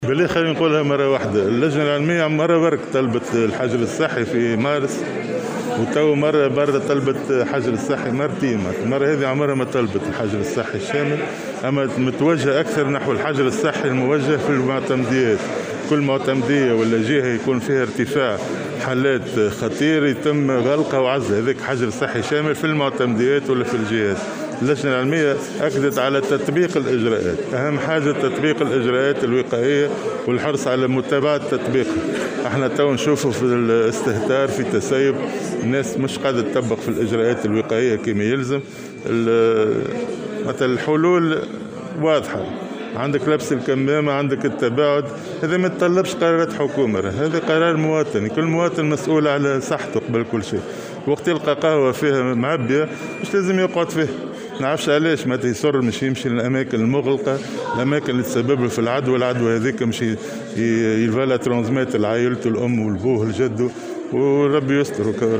وأضاف في تصريح اليوم لمراسل "الجوهرة أف أم" على هامش جلسة استماع له صلب البرلمان، أن الحلول واضحة ولا تتطلب قرارات حكومية، مشيرا إلى أن كل مواطن مسؤول عن صحته.وأوضح أنه لابد من التقيّد بالإجراءات الوقائية والحرص على ارتداء الكمامات وغسل اليدين وتجنب الأماكن المغلقة والمكتظة.